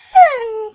Sound / Minecraft / mob / wolf / whine.ogg
whine.ogg